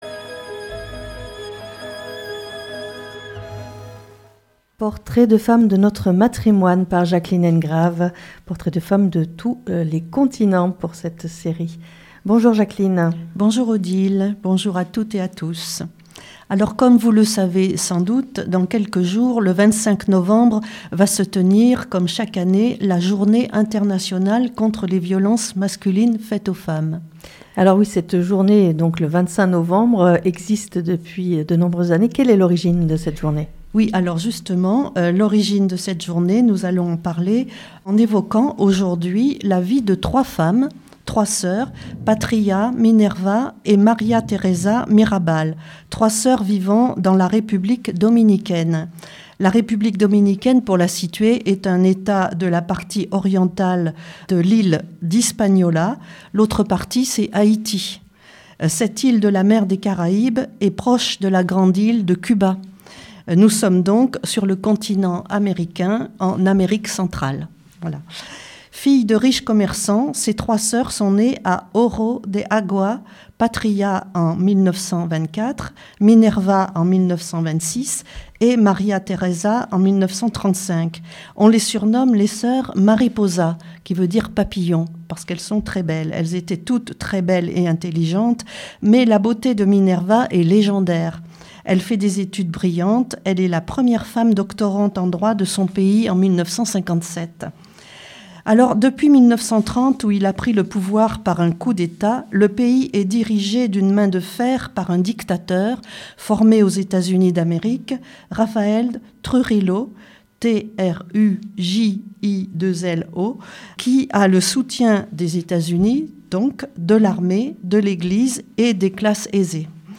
INAUGURATION – BANC ROUGE 25 NOVEMBRE 2024 Hommage à toutes les femmes victimes de féminicides et de violences masculines.